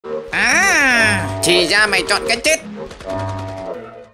Á à thì ra mày chọn cái chết – Sound effect
Thể loại: Câu nói Viral Việt Nam
a-a-thi-ra-may-chon-cai-chet-sound-effect-www_tiengdong_com.mp3